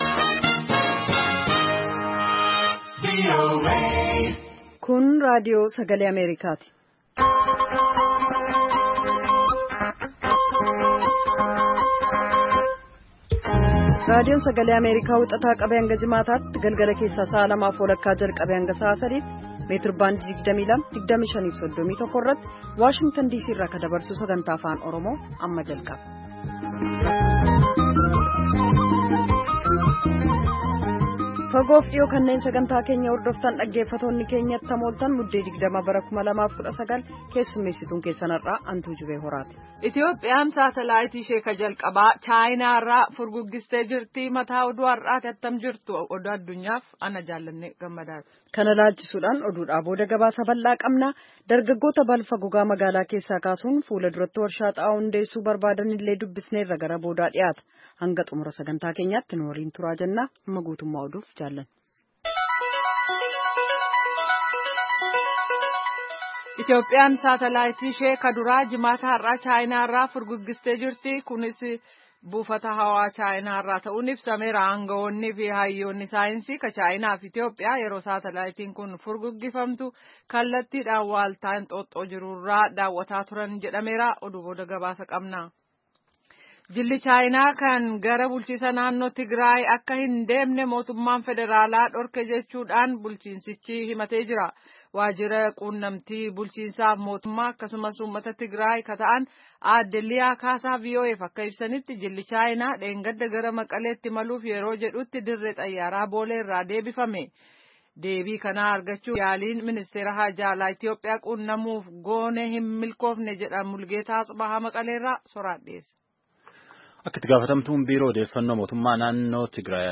Paartileen Siyaasaa fi Ogeeyyiin Miidiyaa dhimma egeree Itiyoophiyaa irratti mari’atan. Akka lakkoobsa Itiyoophiyaatti bara 2032tti haalawwanm biyyattiin keessa dabarti jedhanii yaadawwan adda addaa dhiheessan irrattis marii bal’aa geggeessanii jiru.